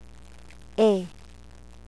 tsayreh "ey" as in they